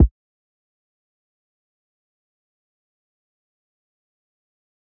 MB Kick (2).wav